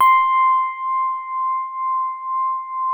FINE SOFT C5.wav